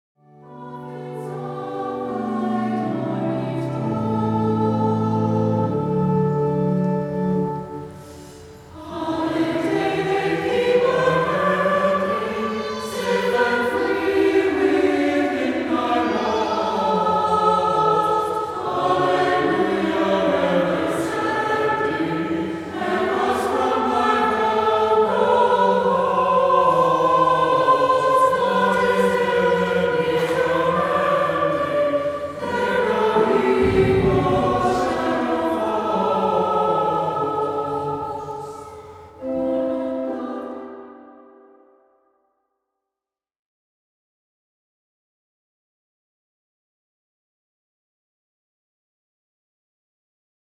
The arrangement is for two voices: all females on the top line, all males on the bottom line.
The odd verses can be sung SATB (unaccompanied), or they can be sung in unison with the organ accompaniment provided: * PDF Download • J ERUSALEM L UMINOSA (English + Latin) —“Jerúsalem Luminósa” • 2-Voice Arrangement of with optional SATB sections.